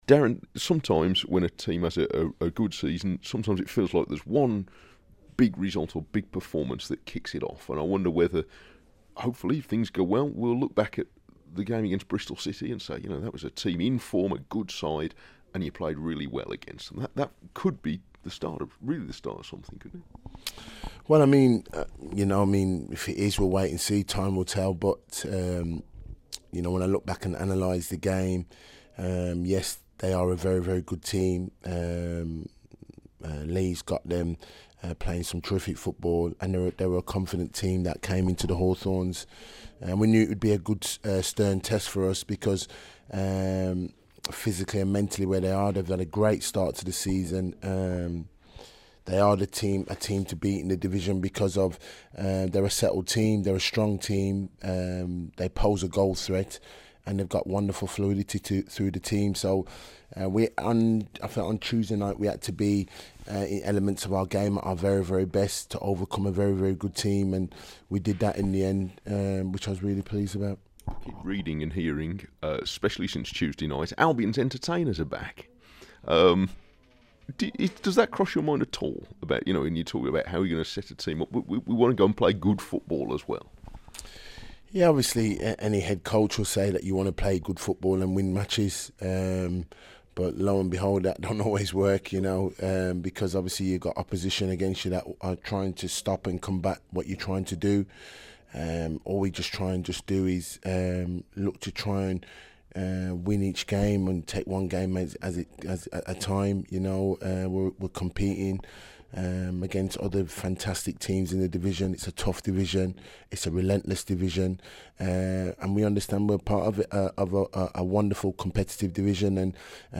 Albion head coach Darren Moore talks to BBC WM in advance of Saturday's home game against Millwall about his team playing entertaining football, whether he's establishing his own pattern of play yet, and whether it was a difficult decision about Craig Dawson being captain after the tension around him in the summer.